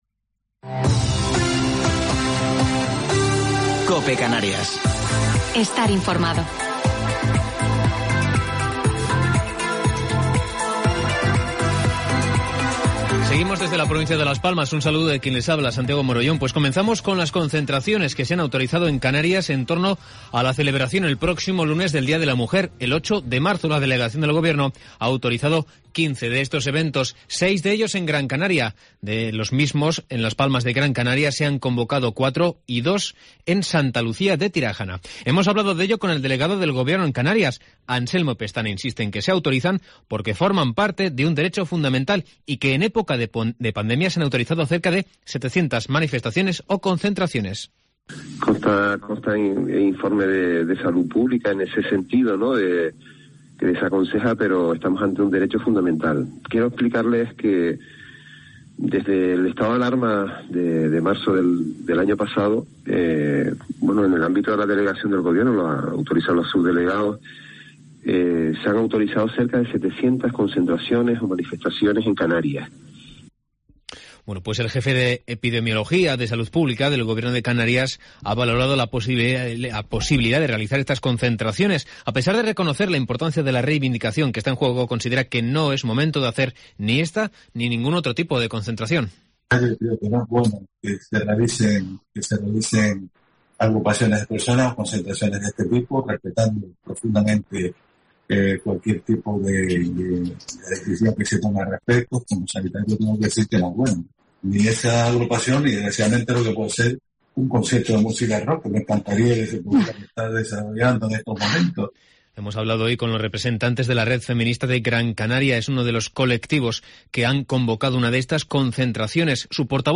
Informativo local 5 de Marzo del 2021